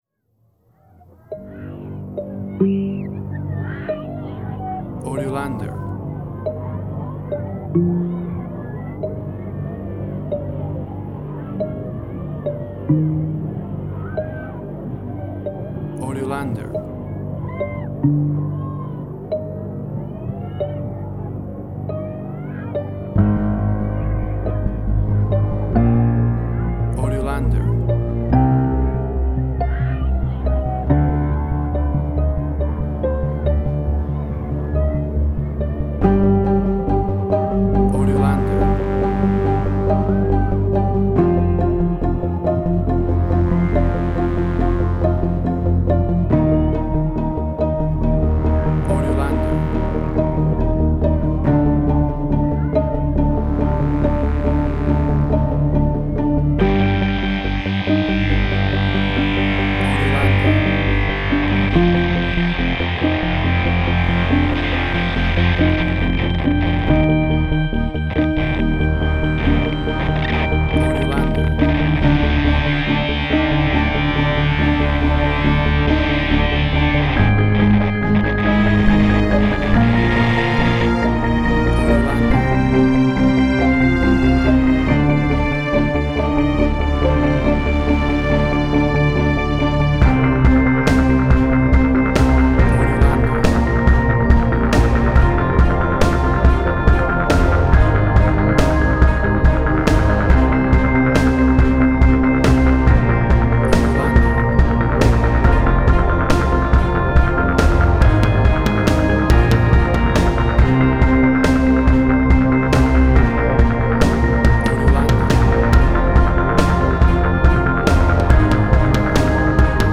emotional music
Tempo (BPM): 140